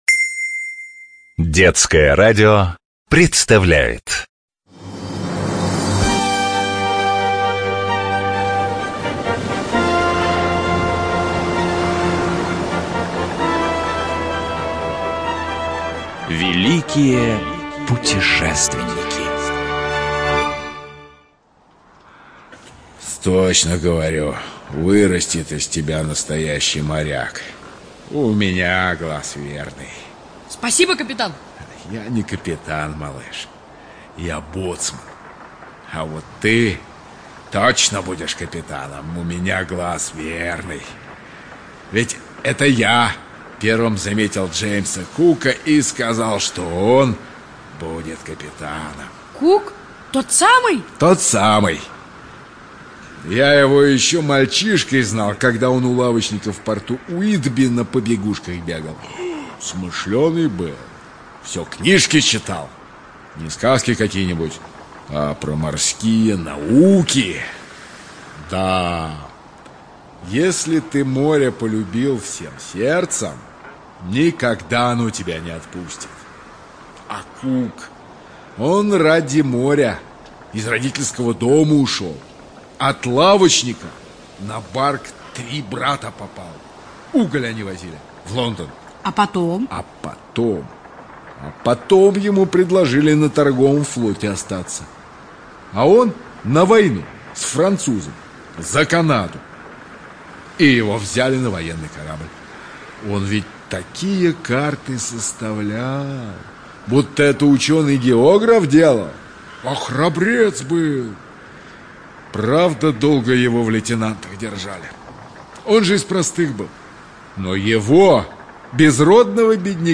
НазваниеВеликие путешественники. Цикл радиопередач